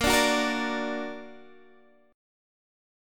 Bb+ Chord
Listen to Bb+ strummed